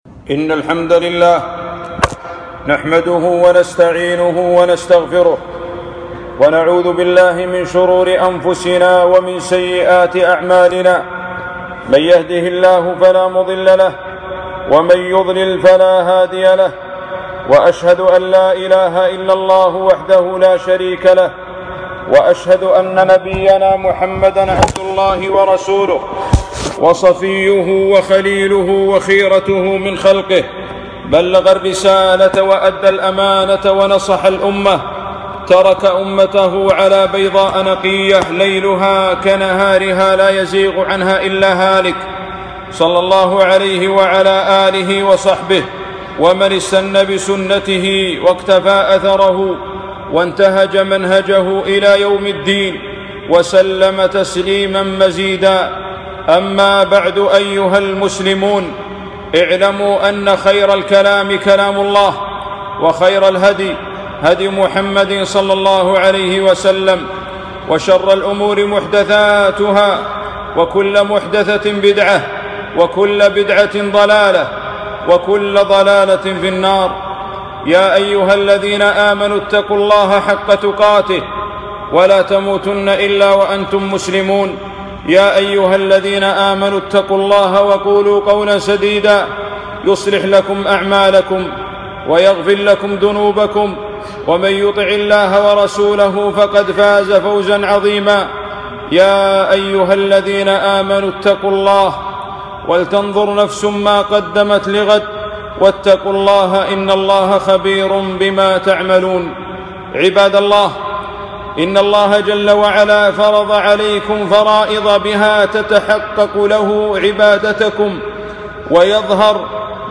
خطبة - شهر رمضان